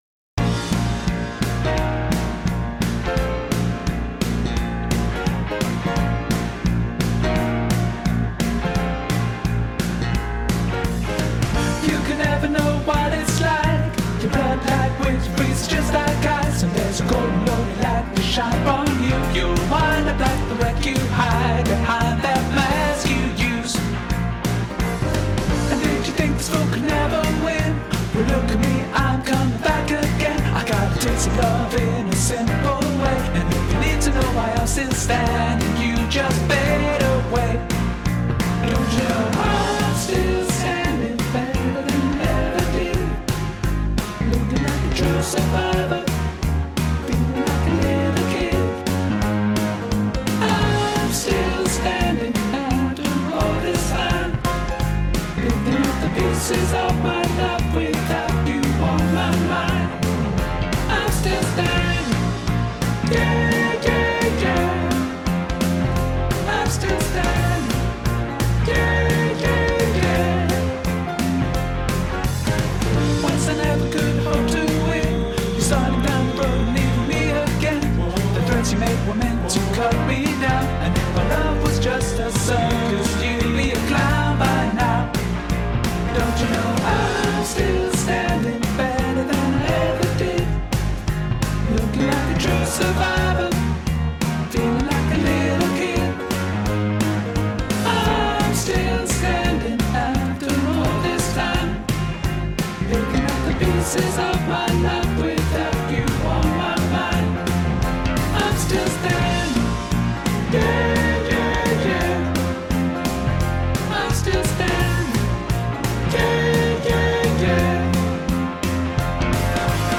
I’m Still Standing Alto Backing Track | Ipswich Hospital Community Choir